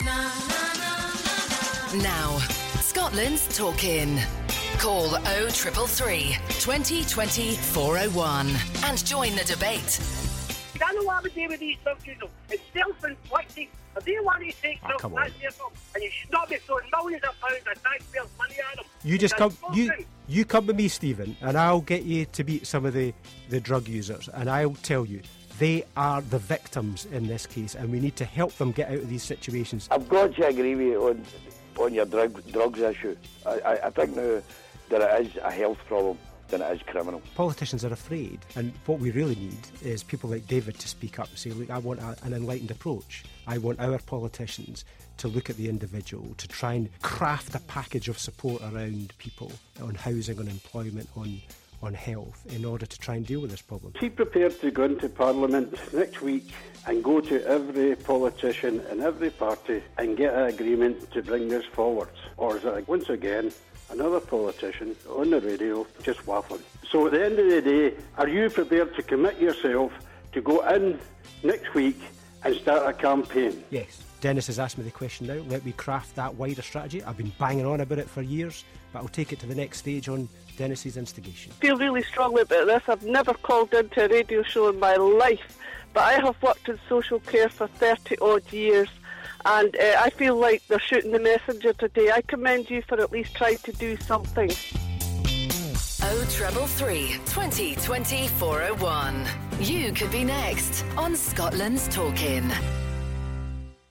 Scottish Lib Dem leader Willie Rennie was talking your calls on Scotland's Talk In - and giving straight answers !